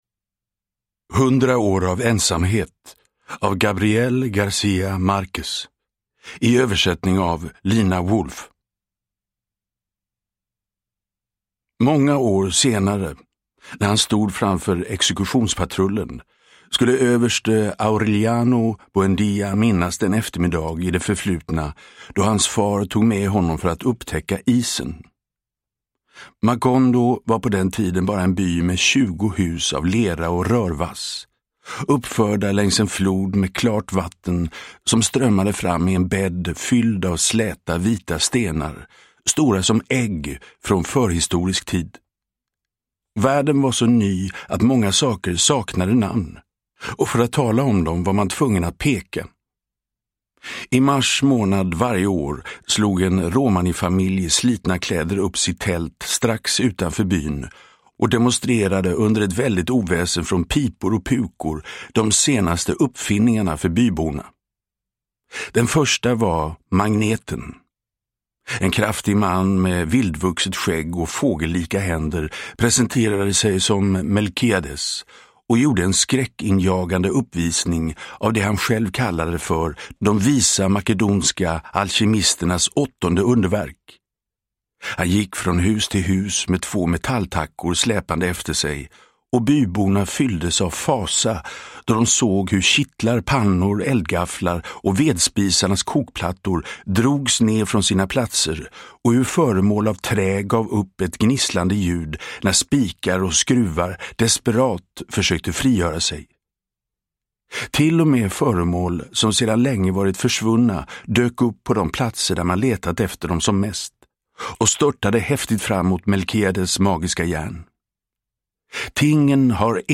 Hundra år av ensamhet – Ljudbok – Laddas ner
Uppläsare: Magnus Roosmann